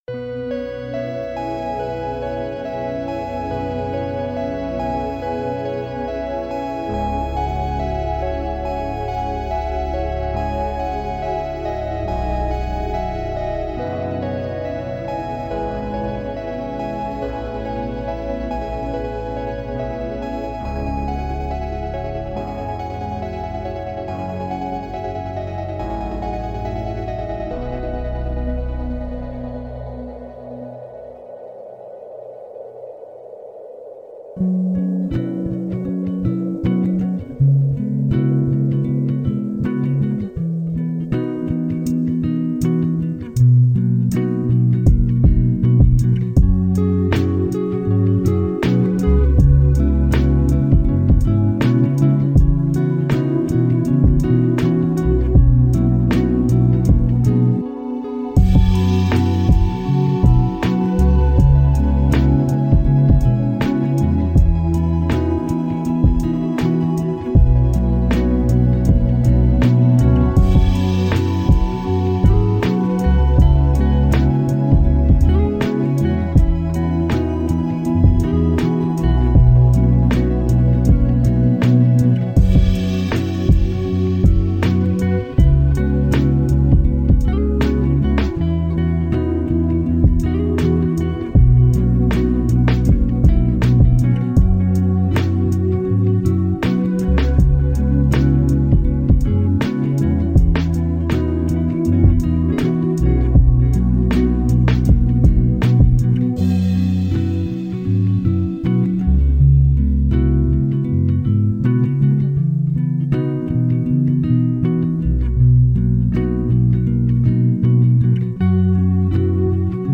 Mozart : Focus Lecture Calme